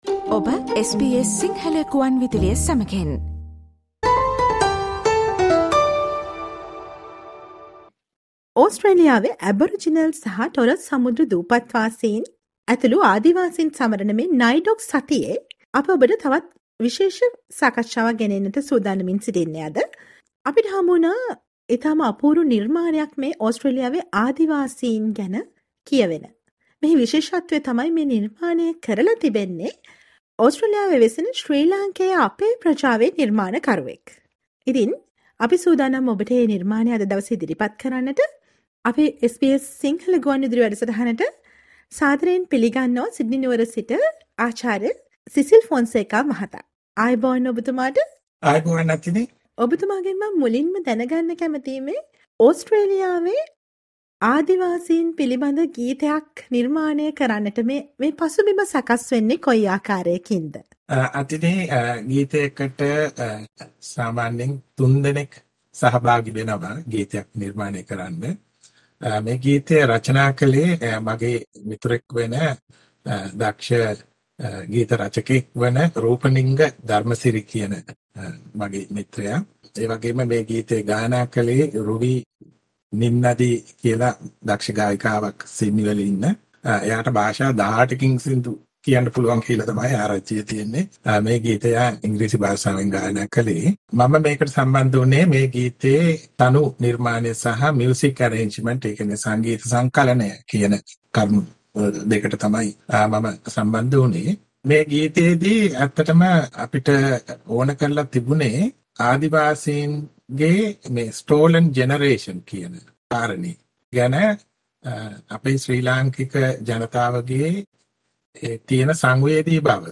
ඔස්ට්‍රේලියානු ආදිවාසීන් තවමත් කණගාටුවෙන් පසු වන දෙයට ඔස්ට්‍රේලියාවේ සංක්‍රමණික ශ්‍රී ලංකේය අපේ ප්‍රජාවත් සංවේදී වන බව සන්නිවේදනය කරන සන්හින්දියාවේ පණිවිඩය රැගත් අපූරු ගීතමය නිර්මාණයක් ගැන කෙටි පිළිසදරකට සවන් දෙන්න ඉහත ඡායාරූපය මත ඇති speaker සලකුණ මත ක්ලික් කරන්න.